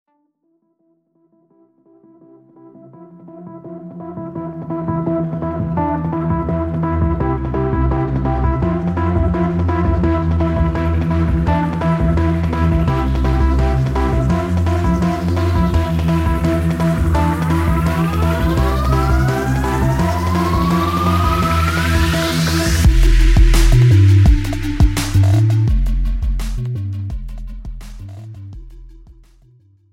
This is an instrumental backing track cover.
• Key – Em
• Without Backing Vocals
• No Fade